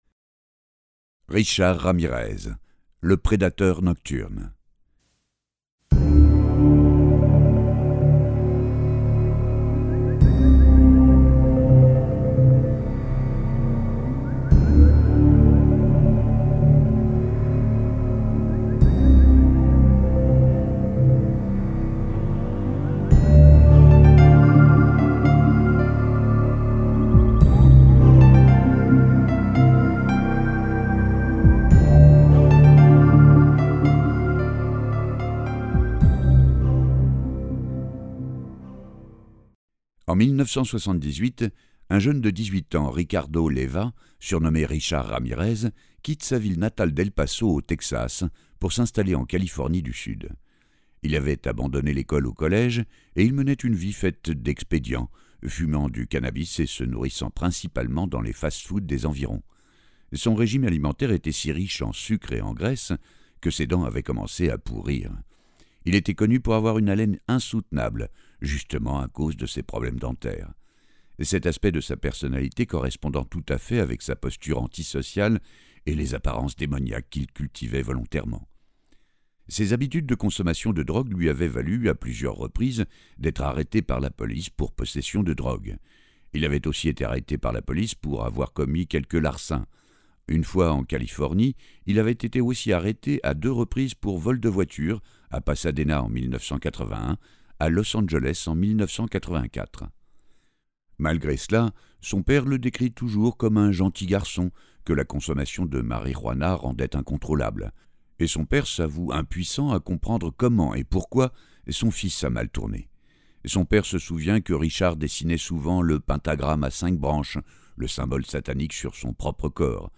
Version intégrale